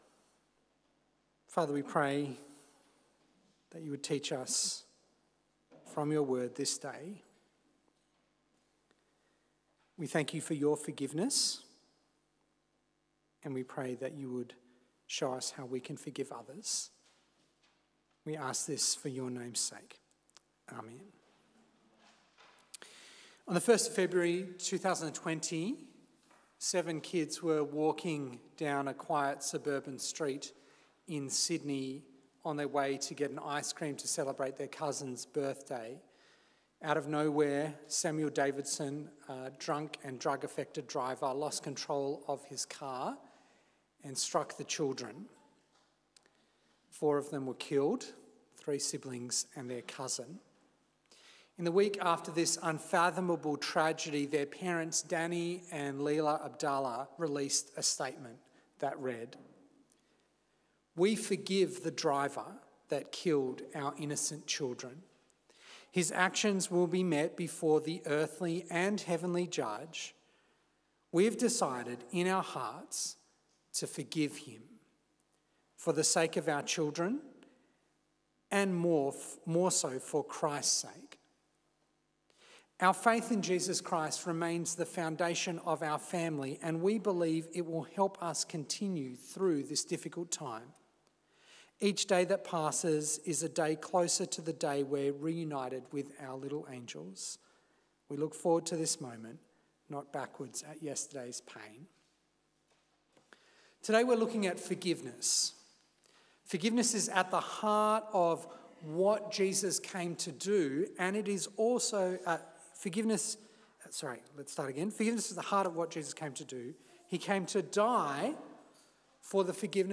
A sermon on Matthew 18